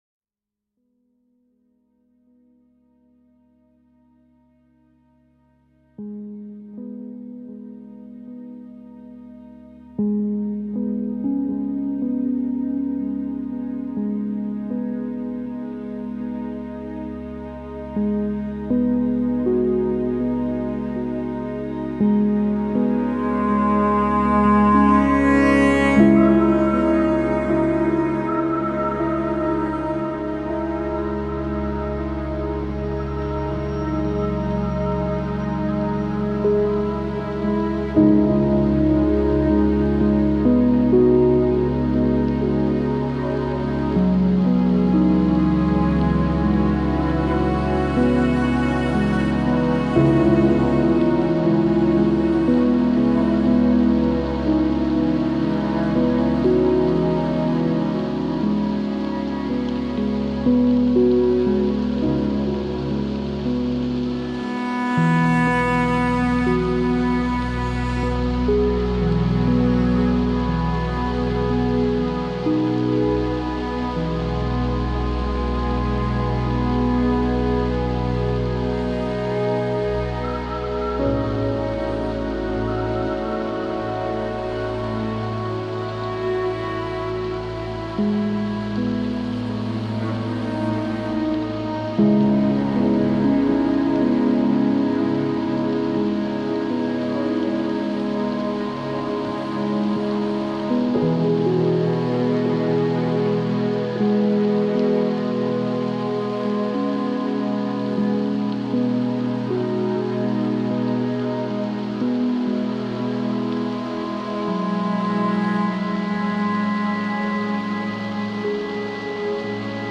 so I added a field recording of woodland rain
Taman Negara soundscape reimagined